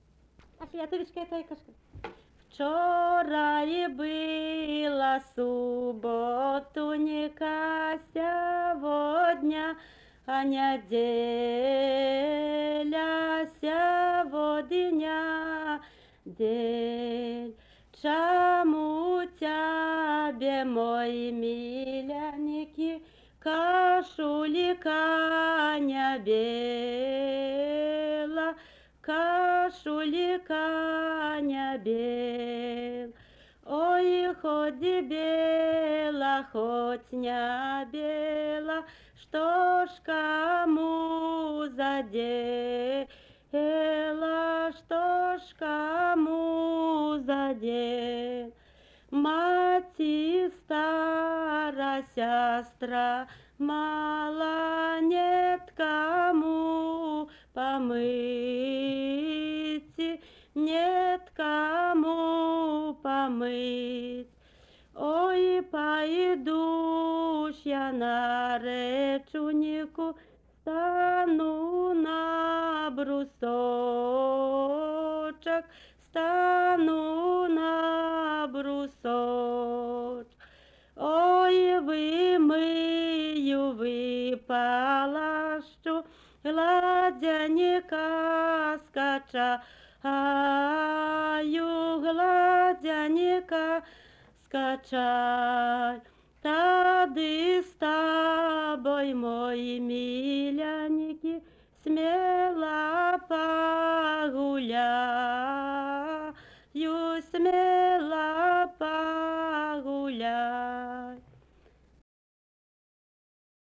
daina
Rimdžiūnai (Rymdzyuny), Baltarusija
rauda kai išneša iš namų